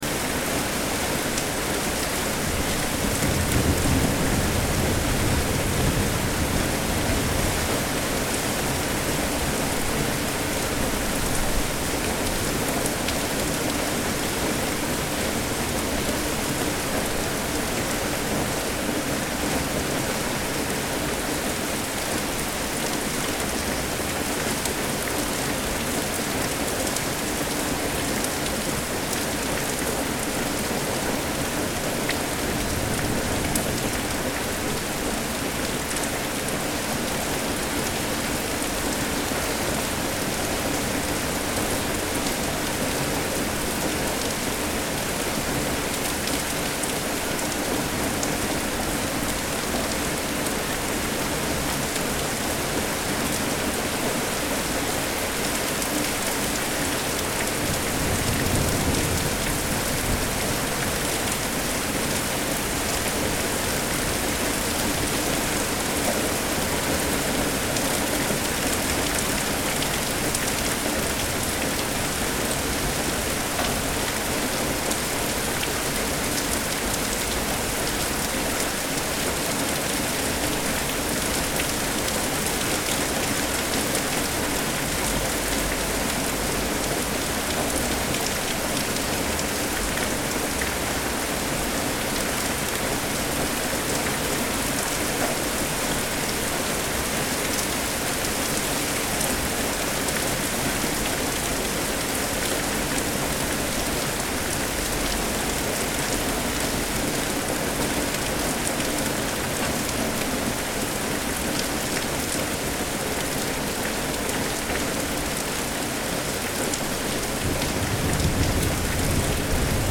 Rain-and-thunder-sound-effect-realistic-rainy-weather-ambience.mp3